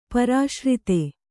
♪ parāśrita